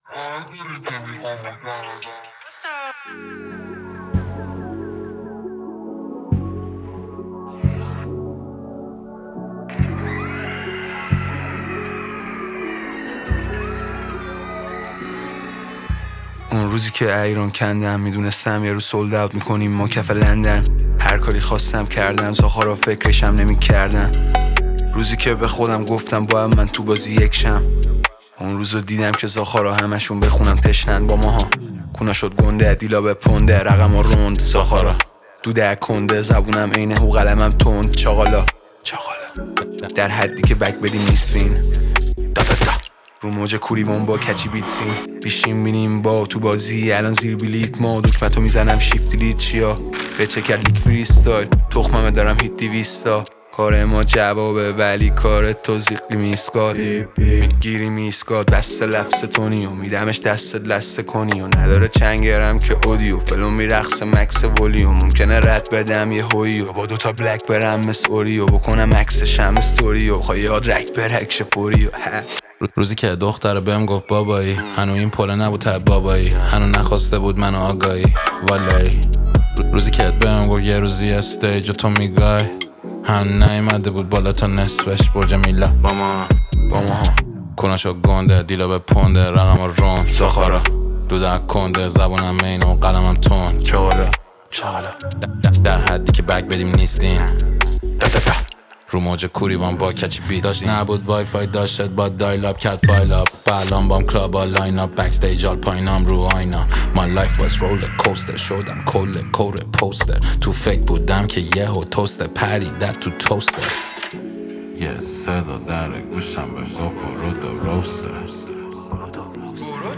شاد و پر انرژی